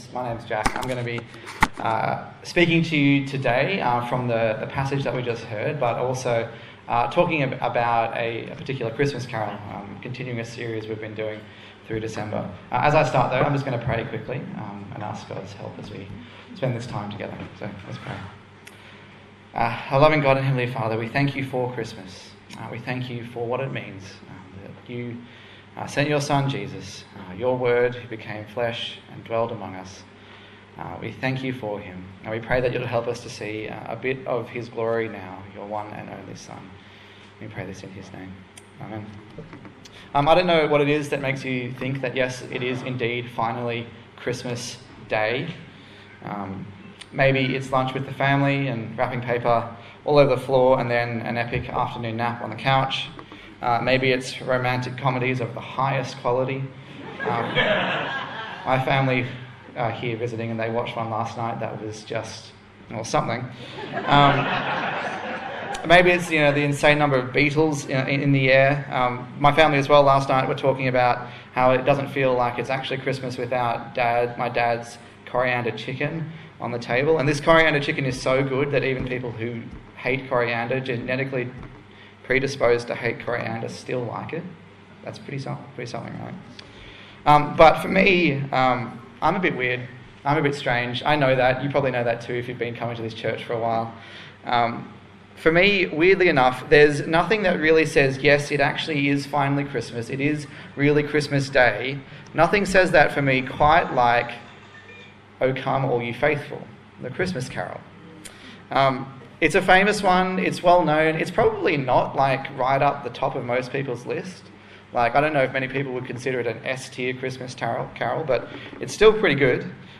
Advent 2024 Passage: John 1:1-18 Service Type: Christmas Day